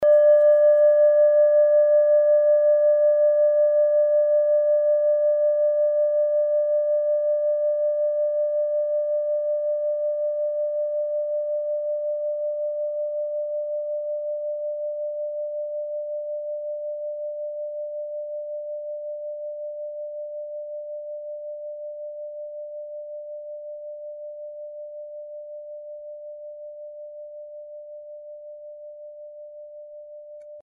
Kleine Klangschale Nr.14 Bengalen Klangschale
Kleine Klangschale Nr.14
Klangschale-Gewicht: 470g
Klangschale-Durchmesser: 13,3cm
Diese Klangschale ist eine Handarbeit aus Bengalen. Sie ist neu und ist gezielt nach altem 7-Metalle-Rezept in Handarbeit gezogen und gehämmert worden.
kleine-klangschale-14.mp3